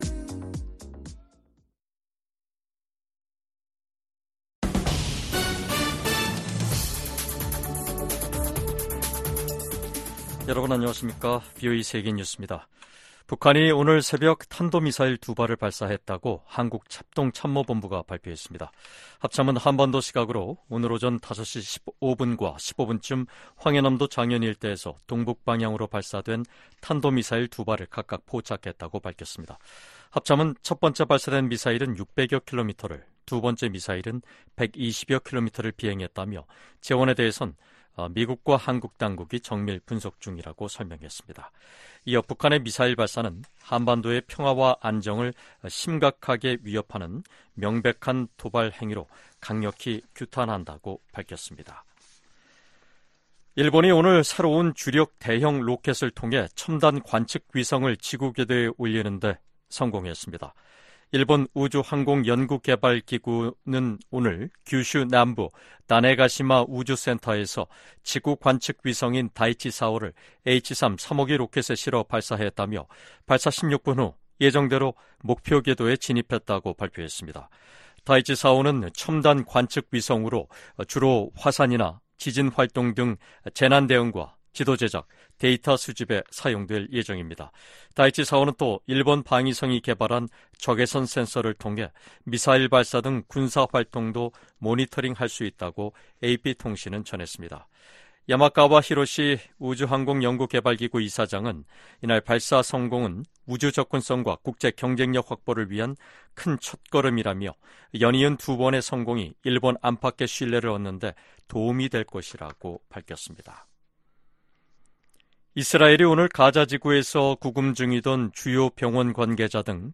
VOA 한국어 간판 뉴스 프로그램 '뉴스 투데이', 2024년 7월 1일 3부 방송입니다. 북한이 한반도 시각 1일 탄도미사일 2발을 발사했다고 한국 합동참모본부가 밝혔습니다. 유엔 안보리가 공식 회의를 열고 북한과 러시아 간 무기 거래 문제를 논의했습니다. 북러 무기 거래 정황을 노출했던 라진항에서 또다시 대형 선박이 발견됐습니다.